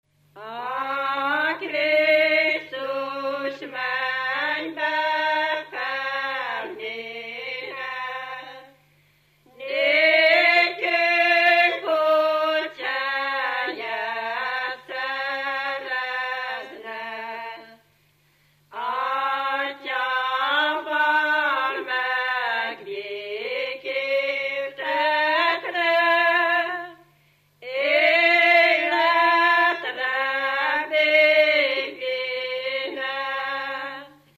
Dunántúl - Verőce vm. - Haraszti
Stílus: 2. Ereszkedő dúr dallamok